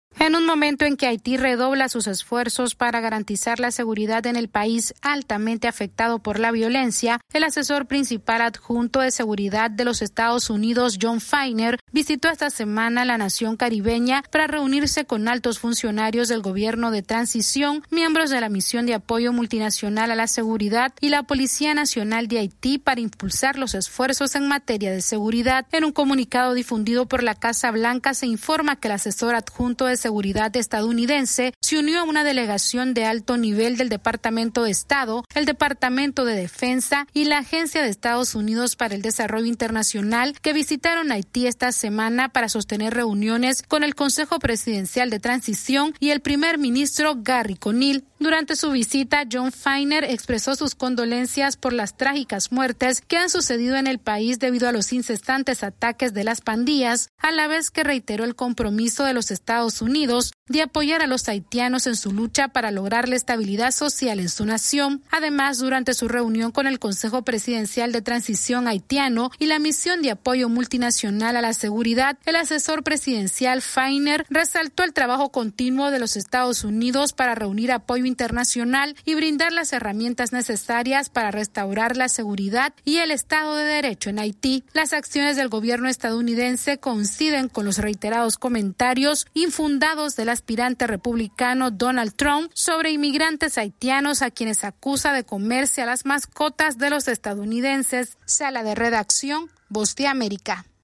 AudioNoticias
Estados Unidos mantiene el diálogo activo con altos funcionarios del gobierno de Haití para apoyar los esfuerzos que buscan fortalecer la seguridad en la nación caribeña. Esta es una actualización de nuestra Sala de Redacción.